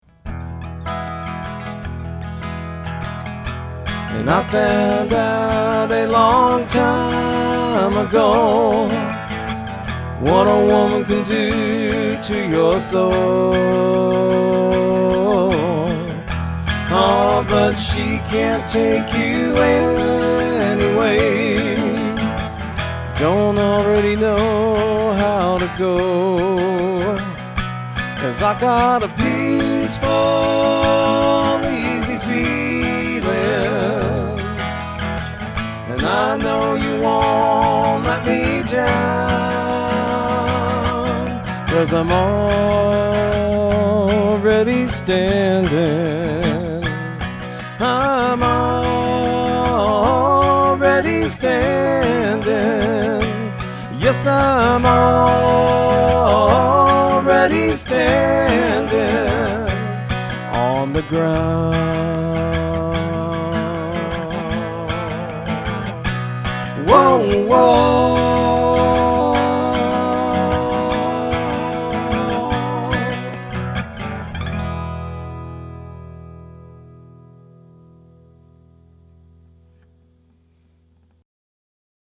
It’s dead quiet.
Here are a few example clips I recorded direct into my DAW: